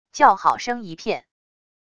叫好声一片wav音频